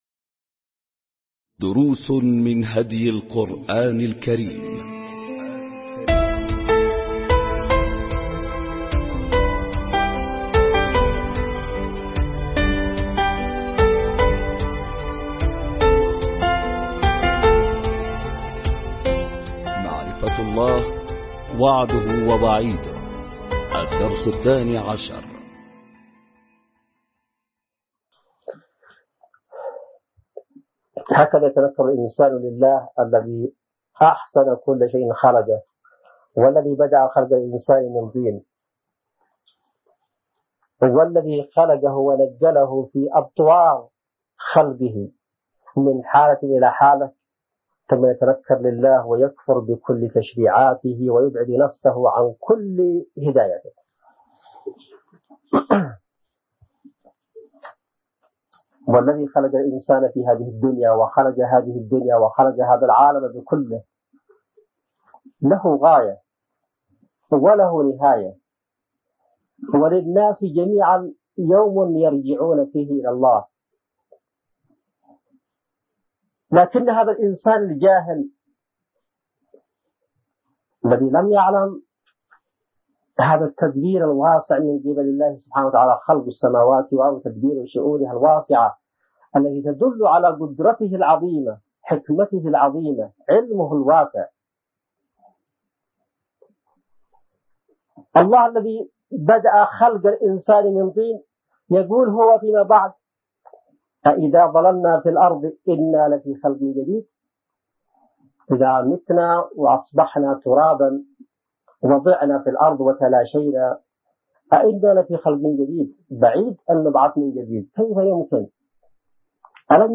دروس من هدي القرآن الكريم معرفة الله – وعده ووعيده – الدرس الثاني عشر ملزمة الأسبوع | اليوم الثالث ألقاها السيد / حسين بدرالدين الحوثي | مؤسسة الشهيد زيد علي مصلح